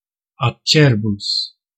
Ääntäminen
UK : IPA : /sɪˈvɪə/